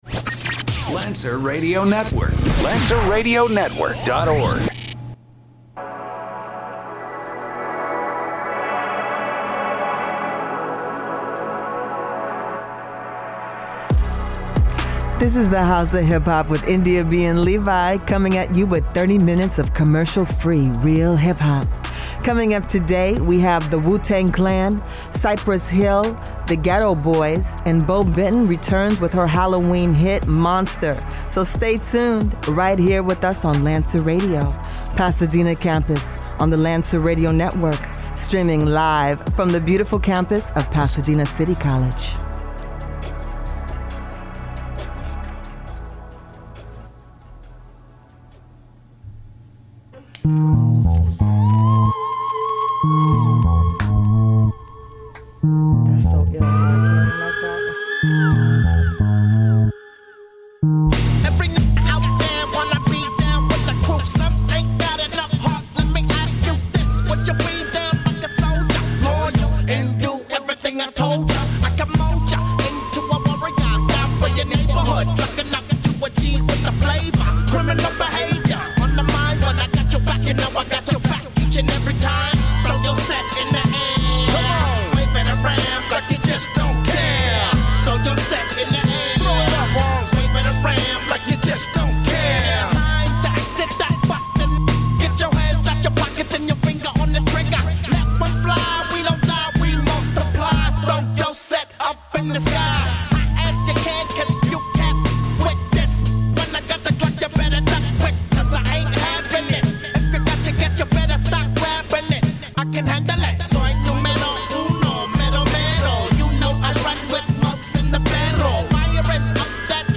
playing the greatest hip hop of yesterday and today.